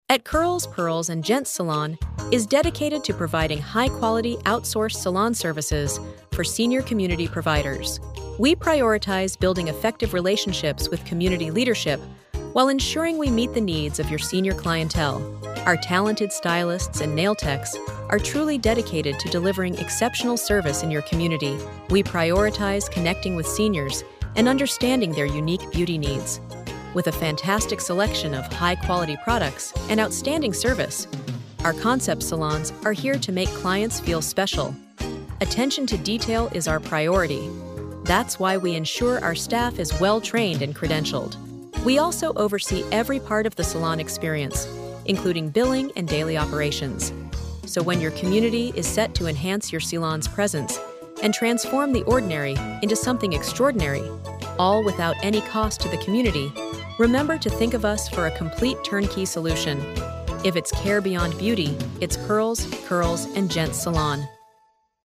CPG-B2B-Female-Updated.mp3